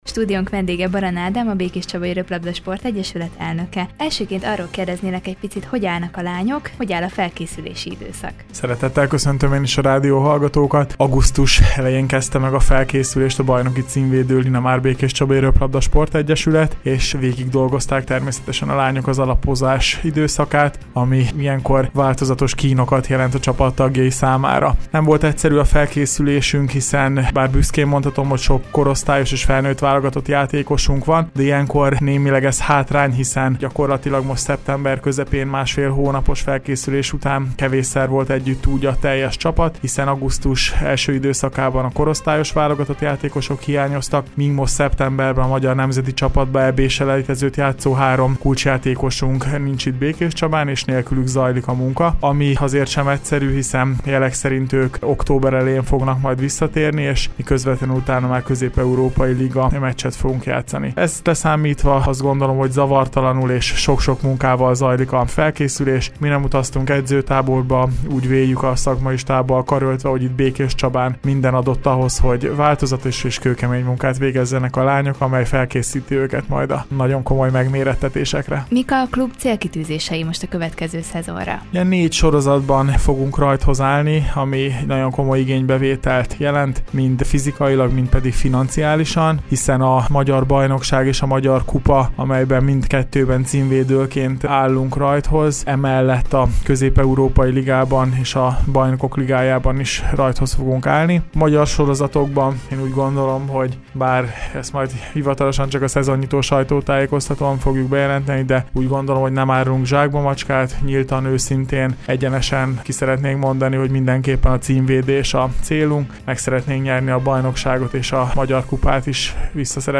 Vele beszélgetett tudósítónk a csapat felkészüléséről valamint az átigazolásokról.